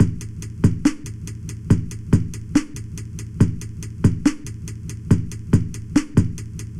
Drum Loop 3.wav